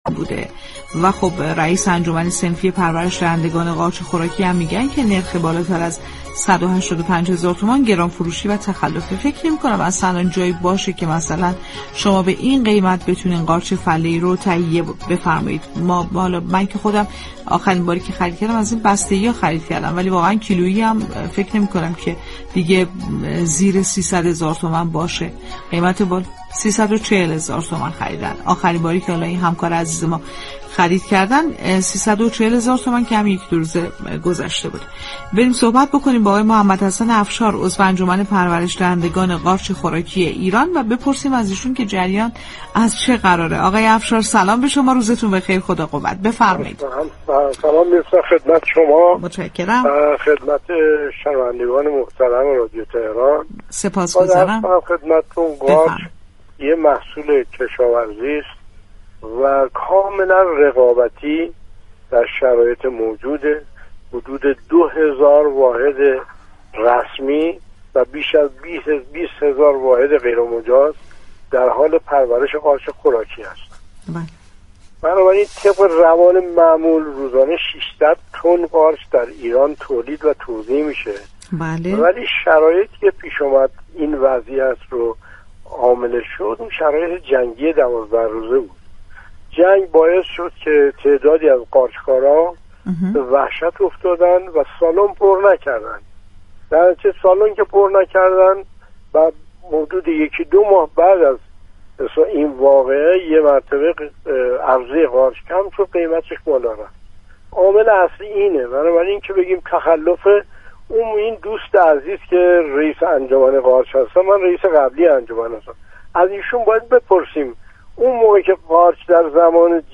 گفت‌وگو با رادیو تهران